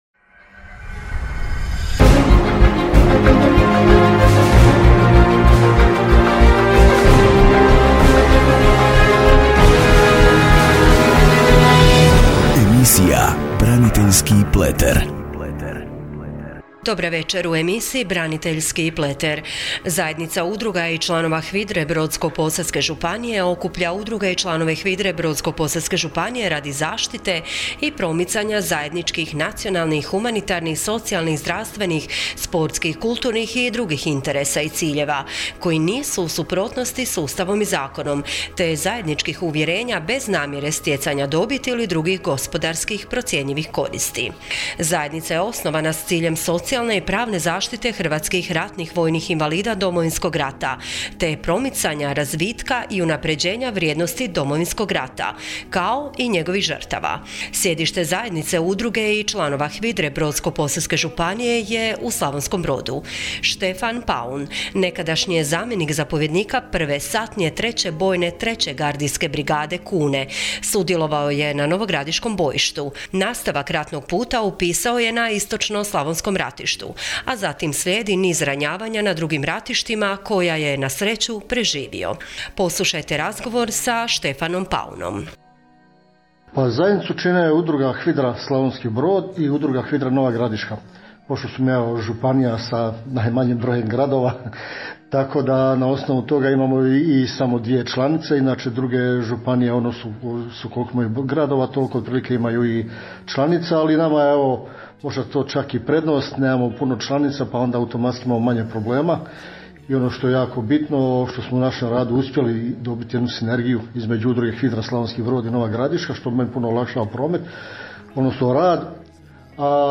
Radijska emisija